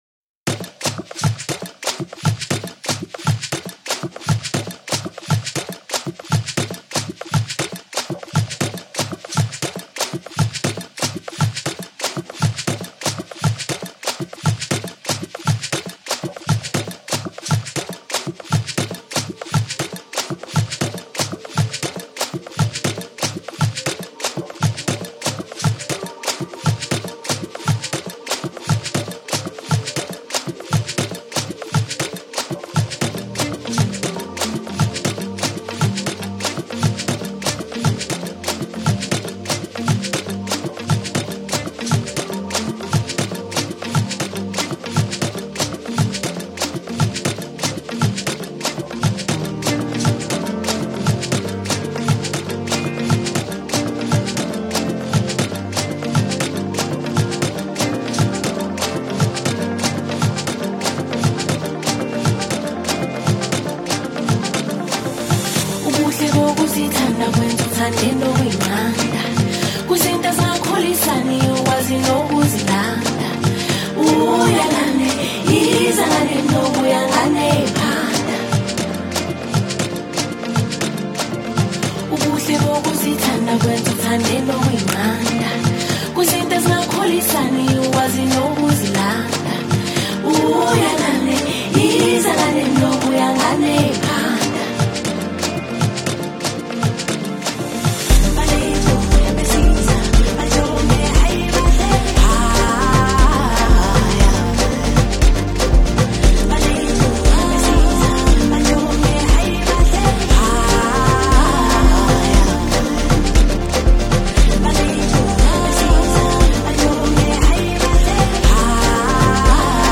Foreign MusicSouth African